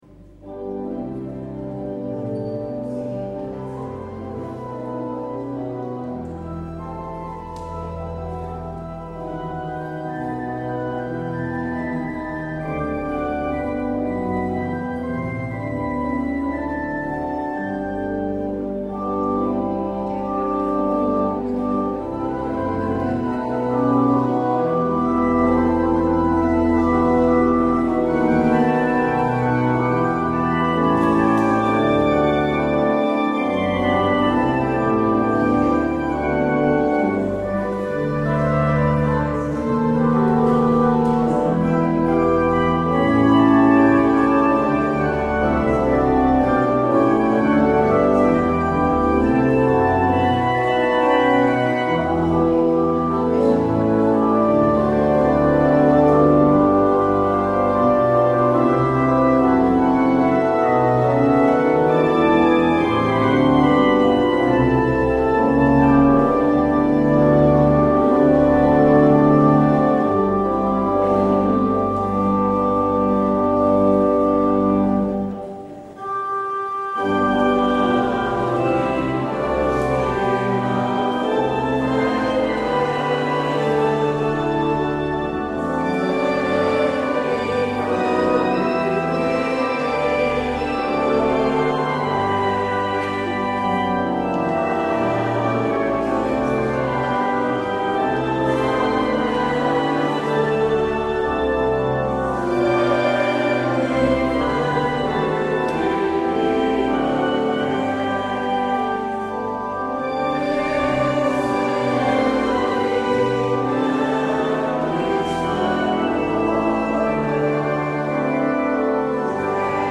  Luister deze kerkdienst hier terug: Alle-Dag-Kerk 22 augustus 2023 Alle-Dag-Kerk https
(‘standaard introductie lekenpreken) Het openingslied is Psalm 65 : 1 en 2.